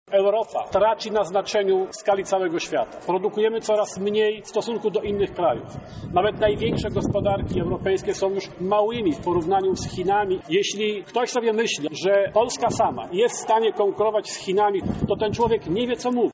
Takimi słowami Janusz Palikot rozpoczął konferencję podsumowującą kampanię wyborczą.